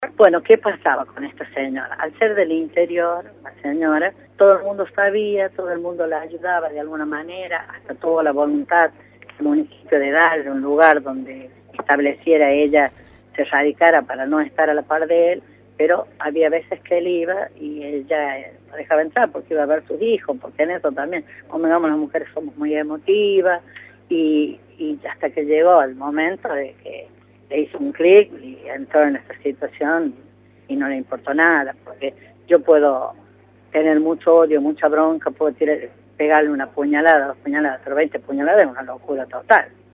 En diálogo con Radio La Red local, la funcionaria dijo frases como “yo puedo tener mucho odio y mucha bronca y pegarle una puñalada a la señora, pero veinte es una locura total”, al hacer referencia a la actitud del asesino.